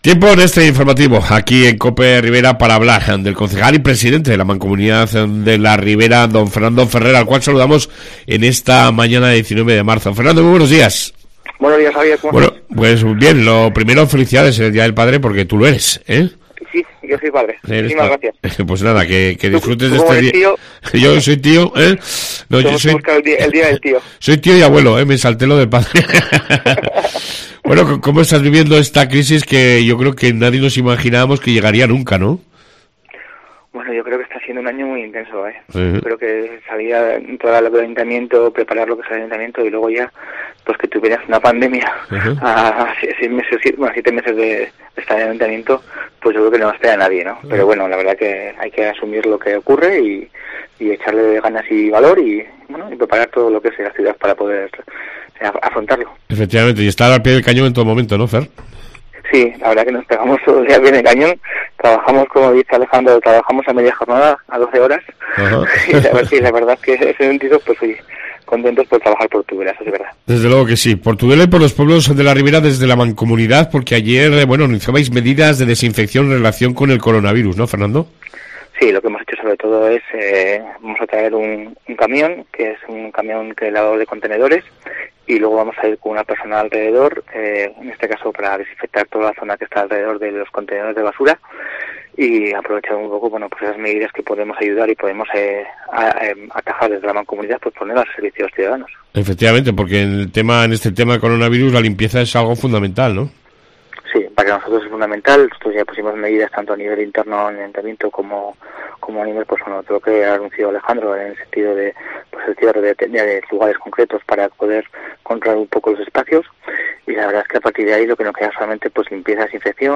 INFORMATIVO COPE RIBERA 19/03